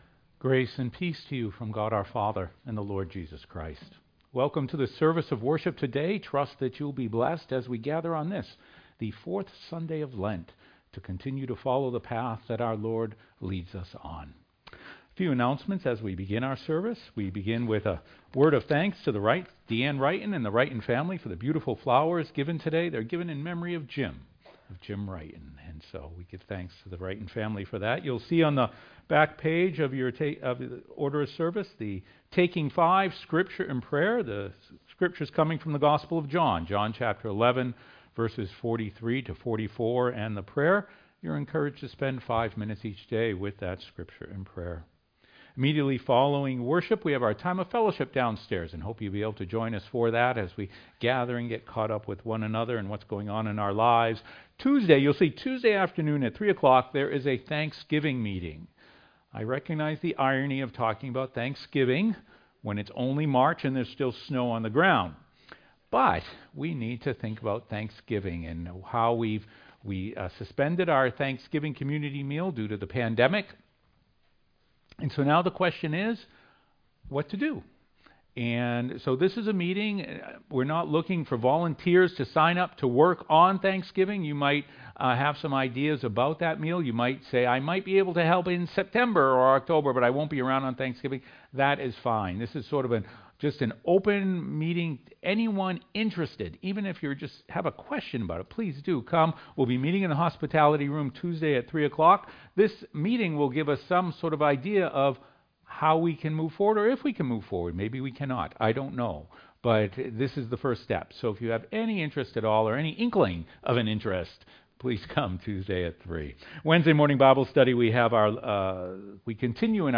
sermon-1.mp3